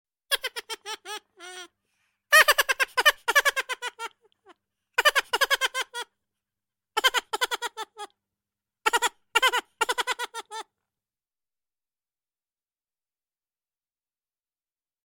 На этой странице собраны разнообразные звуки бурундуков — от веселого стрекотания до любопытного писка.
Вопль бурундука